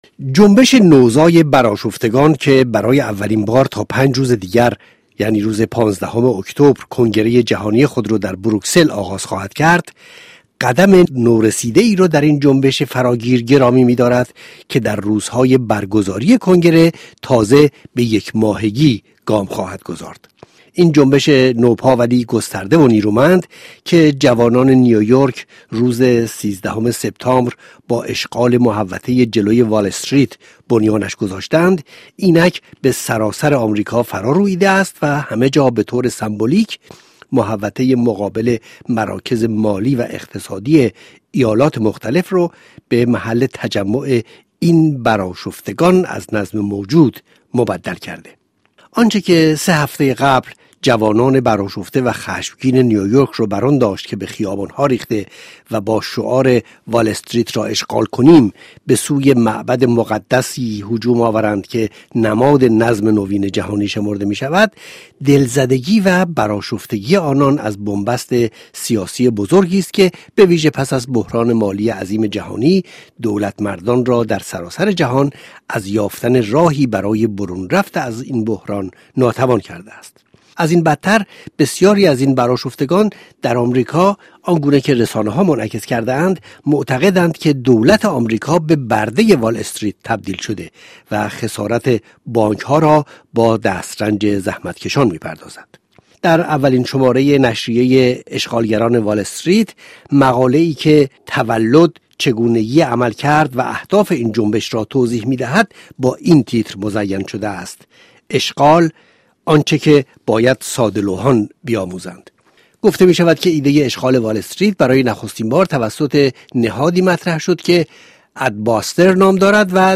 برنامه رادیویی پژواک جامعه را بشنوید